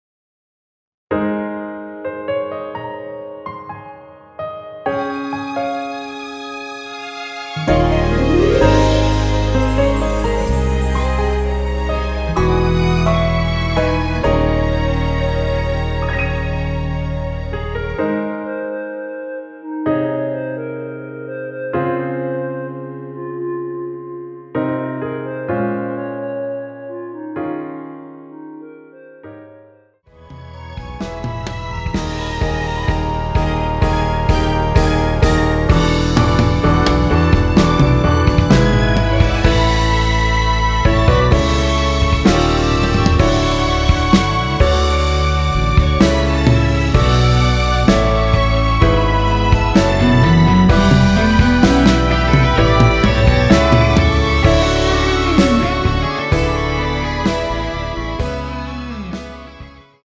원키에서(+5)올린 멜로디 포함된 MR입니다.(미리듣기 확인)
Ab
앞부분30초, 뒷부분30초씩 편집해서 올려 드리고 있습니다.
중간에 음이 끈어지고 다시 나오는 이유는